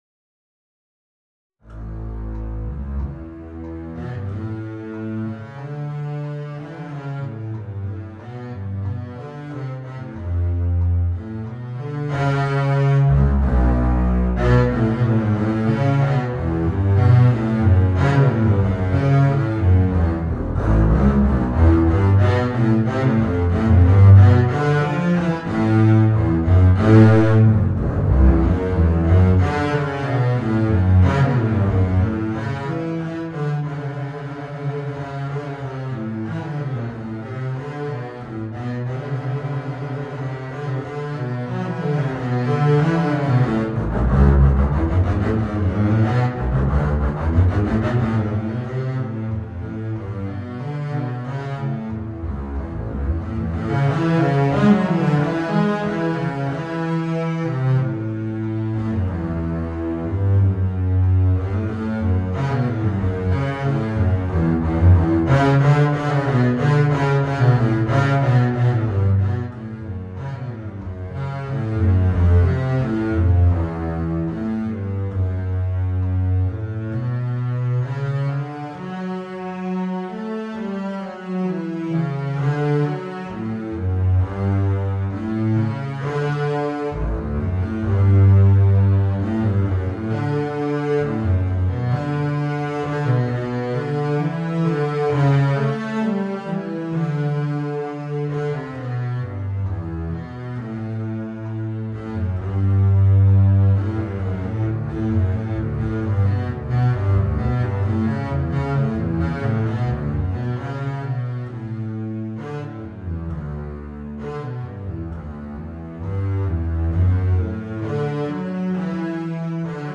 試聴サンプル